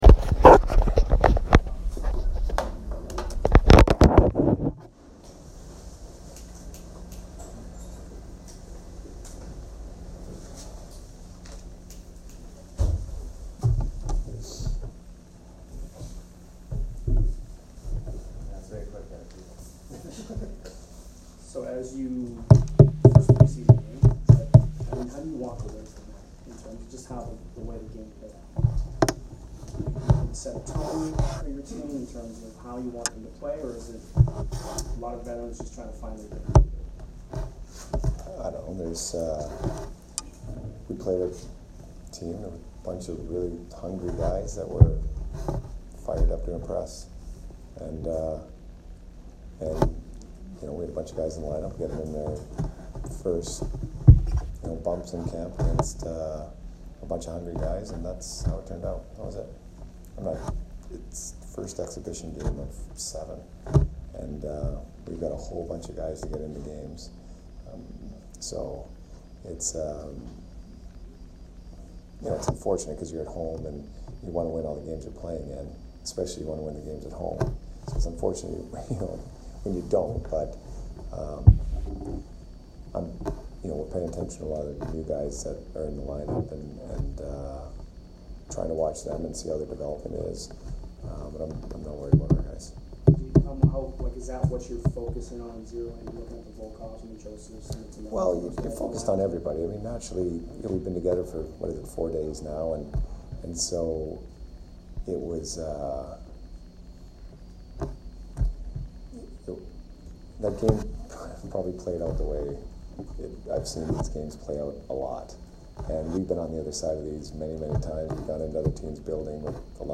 Jon Cooper post-game 9/18